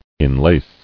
[in·lace]